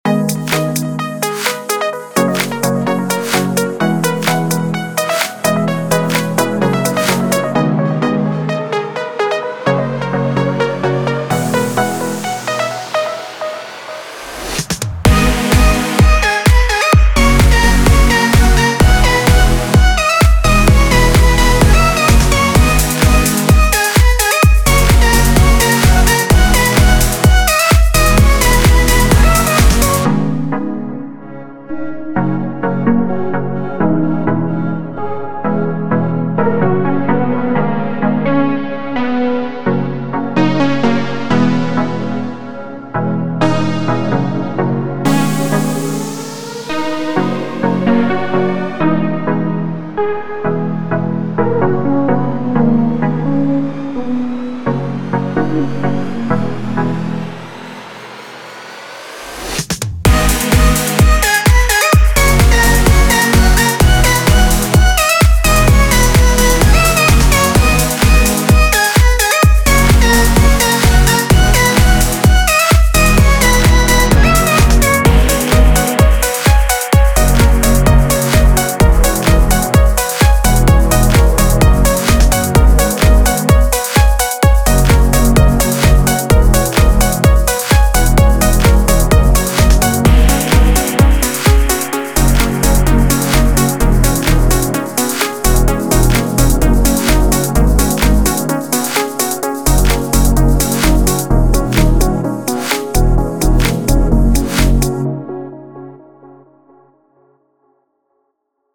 LAのサウンドにインスパイアされて創られた、ハーモニー的にもリッチなパッチとフューチャーベース